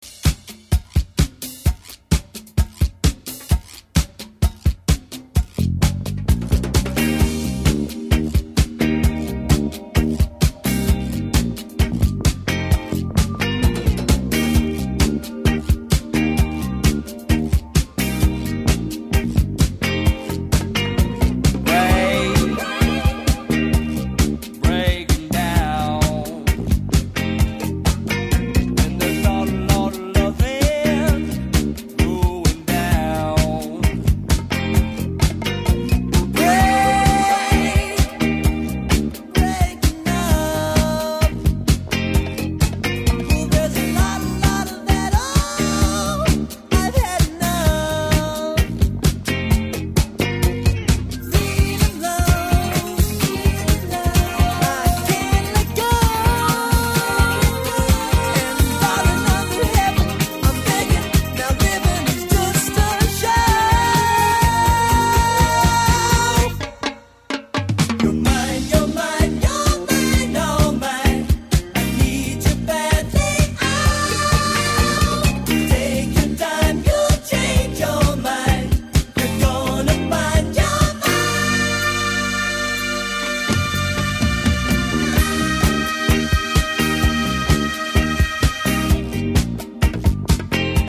a more ‘live’ sounding affair